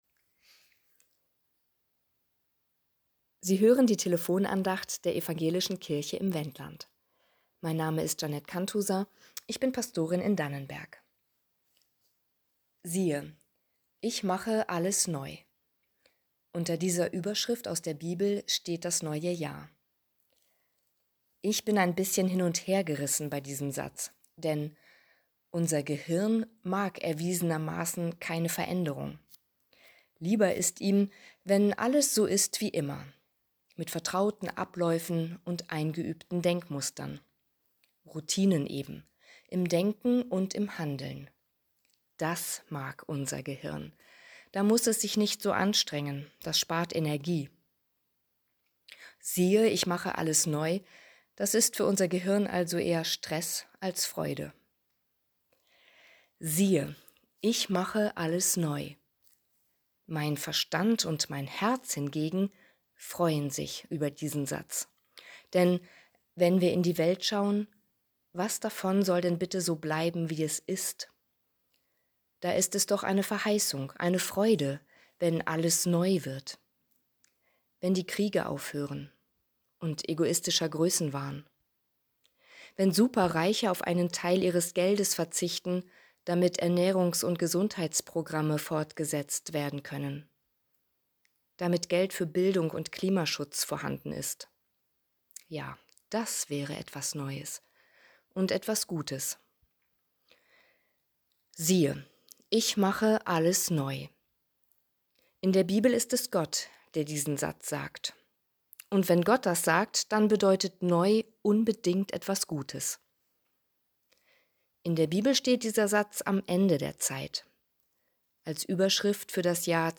Siehe, ich mache alles neu ~ Telefon-Andachten des ev.-luth. Kirchenkreises Lüchow-Dannenberg Podcast